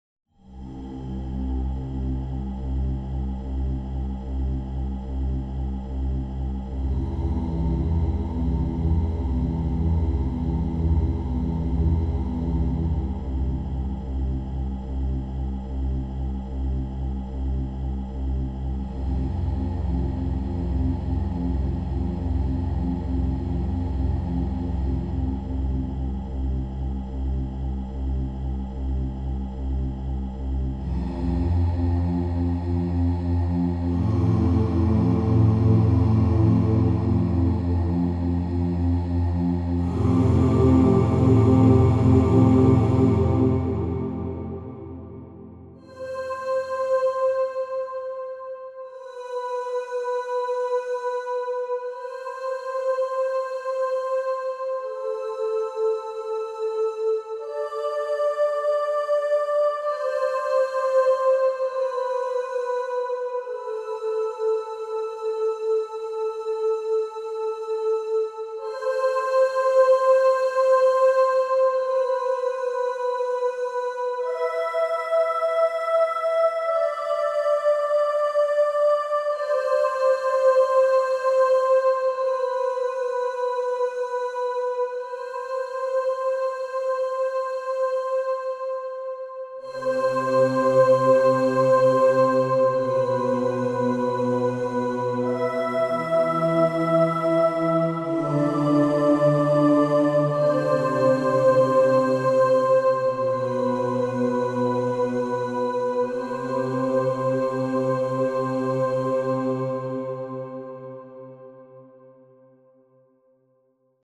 Electronic Music
vocal.mp3